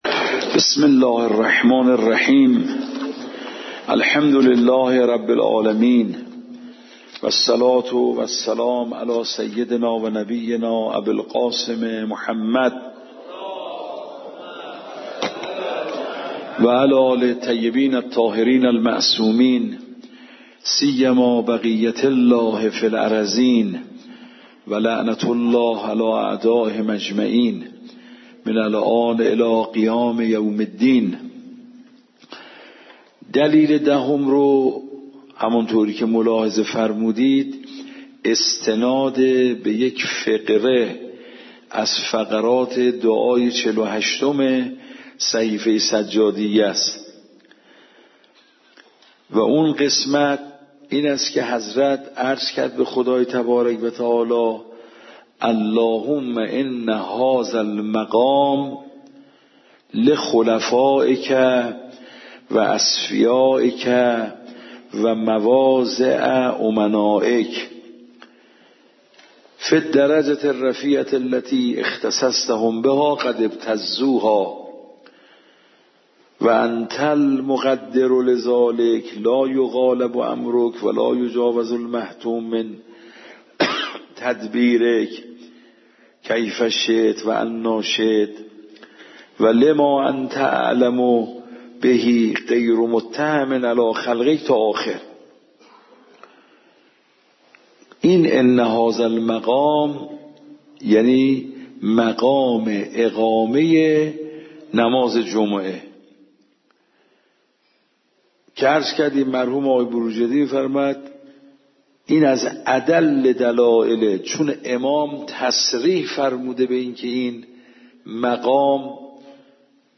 فقه خارج فقه نماز جمعه
صوت درس